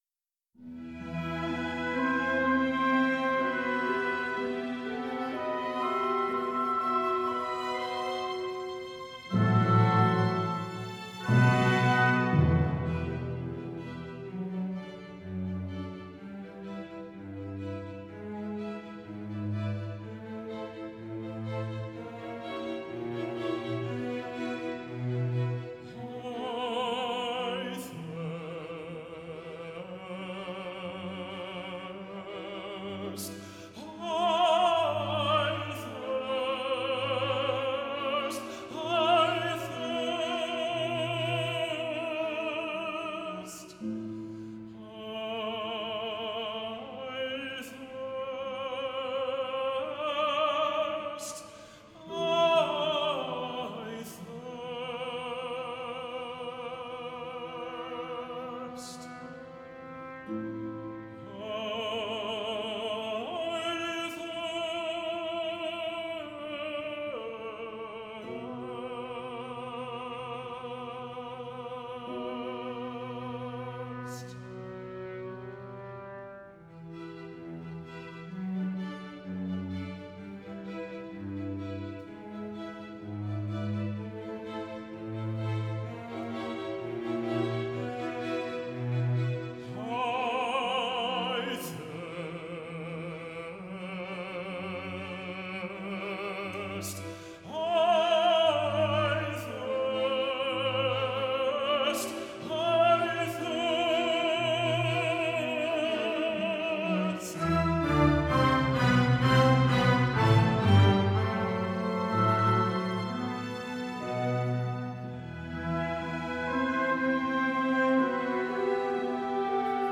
Genere: Choral.